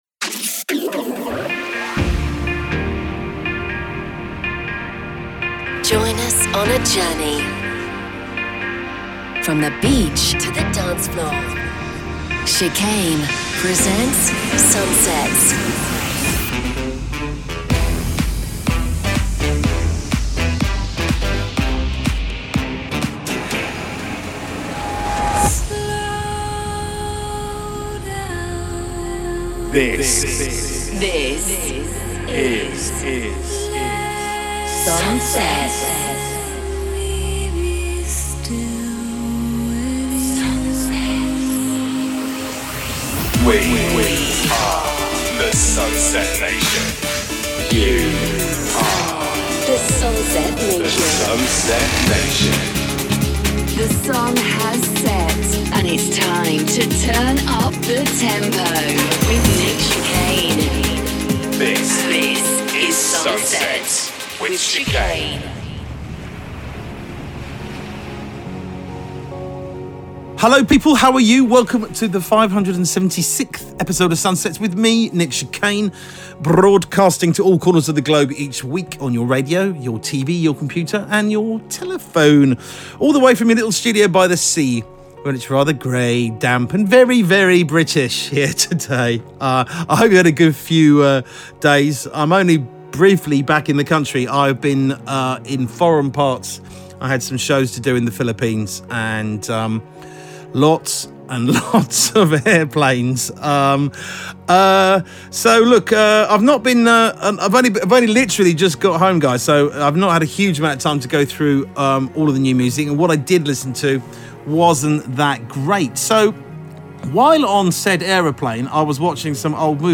From the beach to the dancefloor...
A casa da m�sica eletr�nica no Brasil